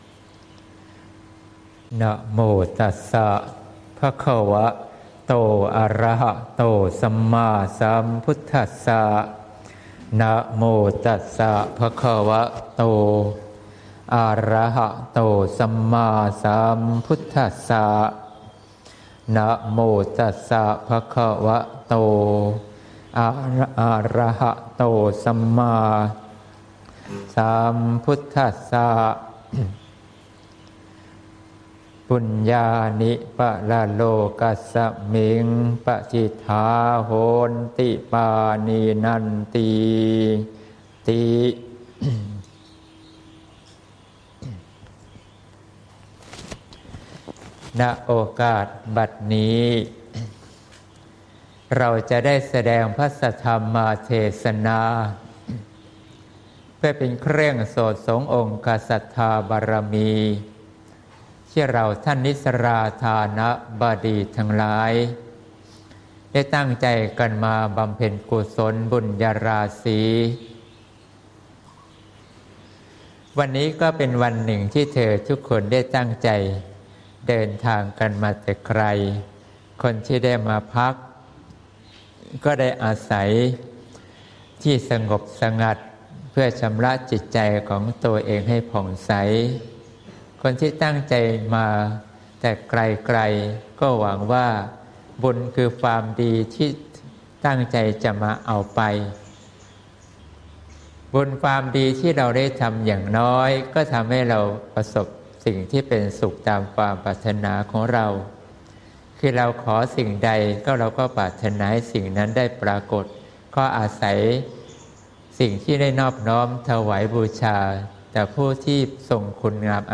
เทศน์ (เสียงธรรม ๑๒ ส.ค. ๖๘)